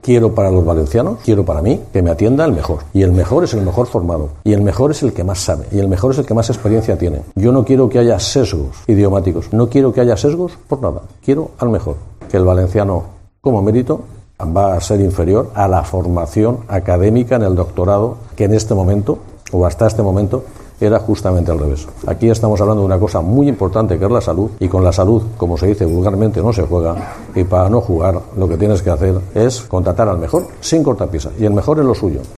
El conseller de Sanidad, Marciano Gómez, explica que la formación puntuará más que saber valenciano